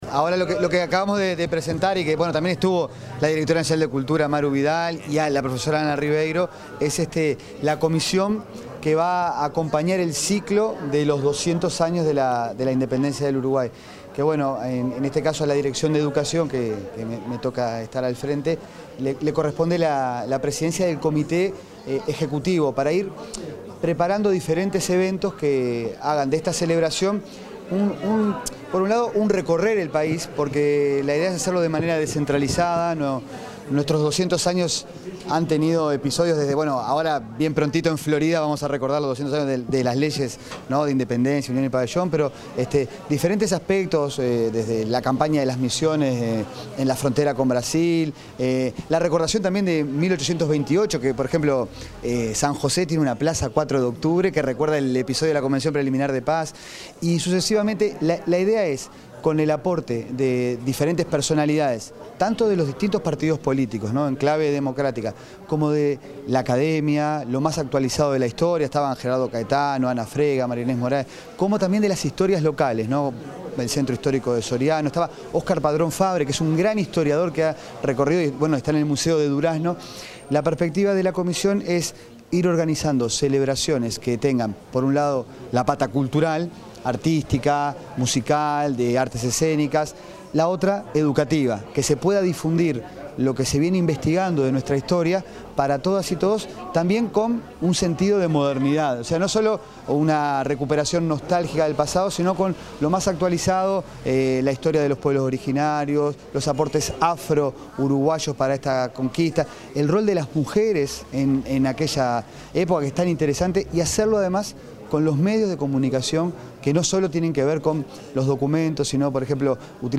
Declaraciones del director nacional de Educación, Gabriel Quirici 20/08/2025 Compartir Facebook X Copiar enlace WhatsApp LinkedIn Tras participar en el lanzamiento de las celebraciones de los 200 años del proceso de creación de la República Oriental del Uruguay, el director nacional de Educación, Gabriel Quirici, respondió preguntas de la prensa.